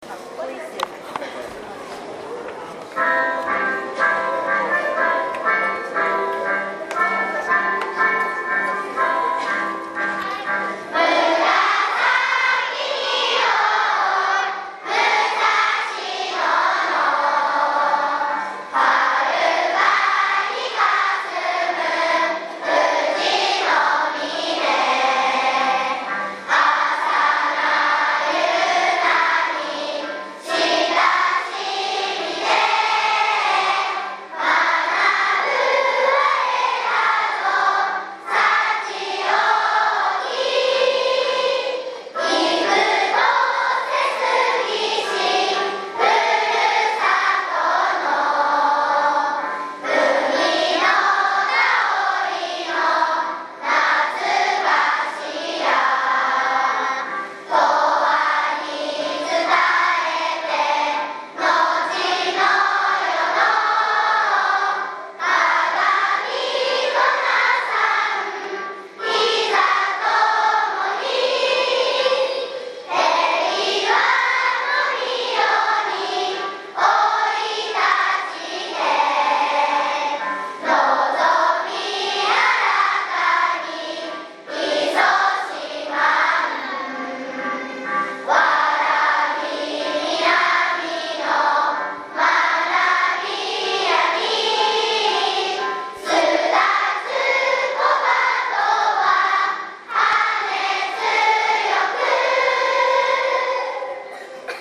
全校がそろって音楽集会を行いました。１年生も、元気に校歌を歌うことができており、驚きました。
今日は姿勢や口の形、発音を気をつけながら歌いました。
0424 音楽集会　校歌.MP3
子供たちの元気な歌声です♪